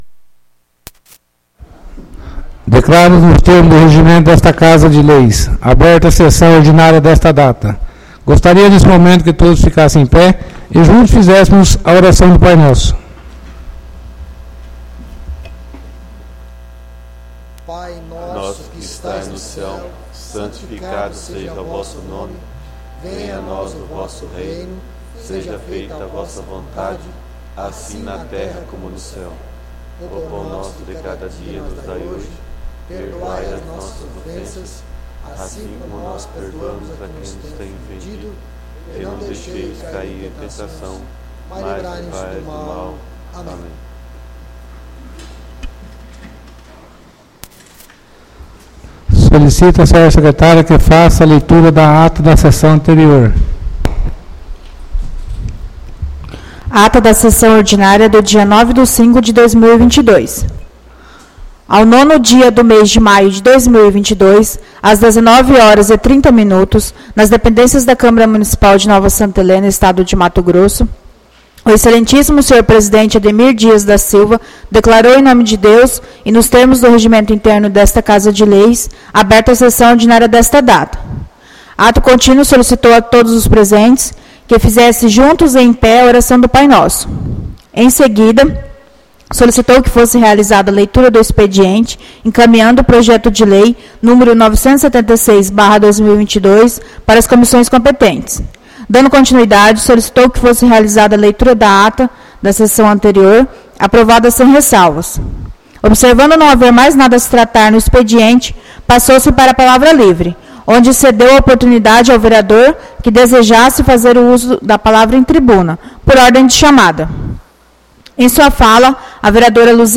ÁUDIO SESSÃO 16-05-22 — CÂMARA MUNICIPAL DE NOVA SANTA HELENA - MT